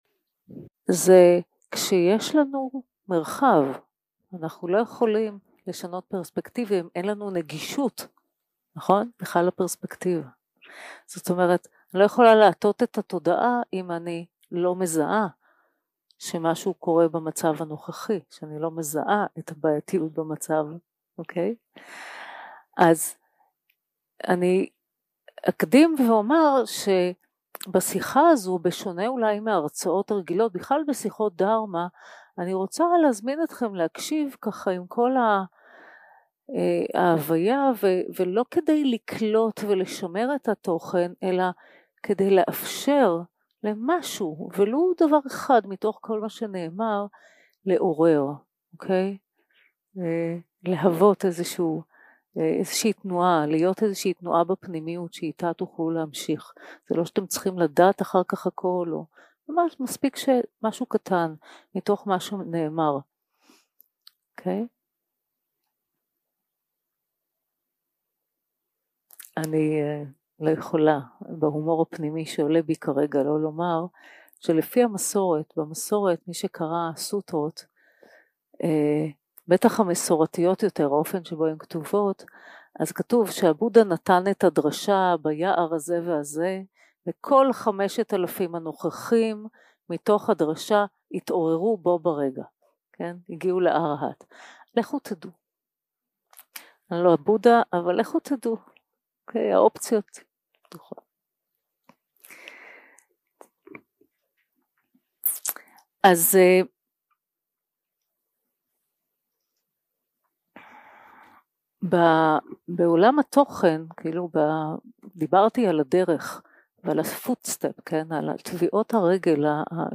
יום 3 - הקלטה 7 - ערב - שיחת דהרמה - מה קורה כשמטים את התודעה
סוג ההקלטה: שיחות דהרמה